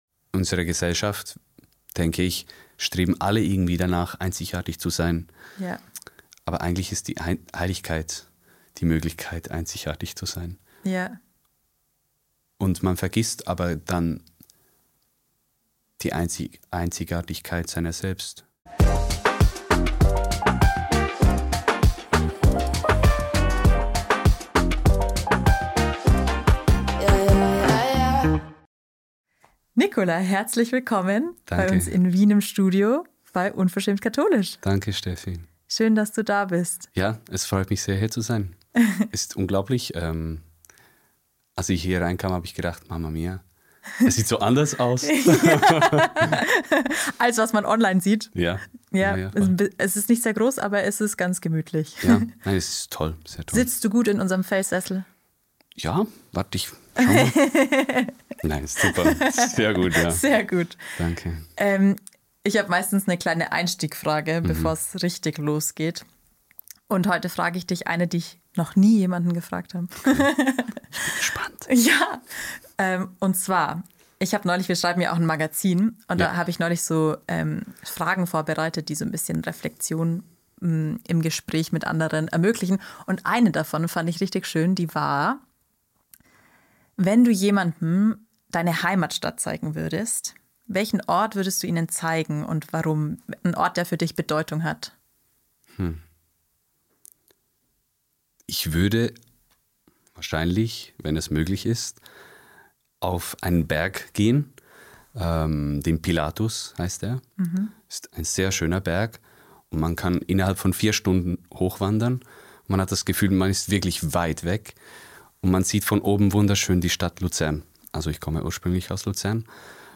Timestamps 00:00 Intro & Chitchat